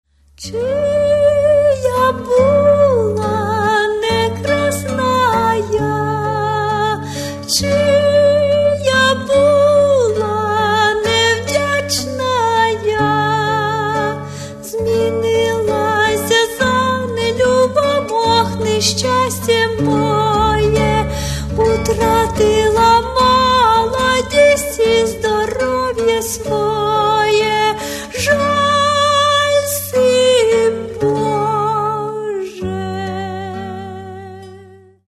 Каталог -> Народная -> Старинная музыка